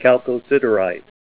Help on Name Pronunciation: Name Pronunciation: Chalcosiderite + Pronunciation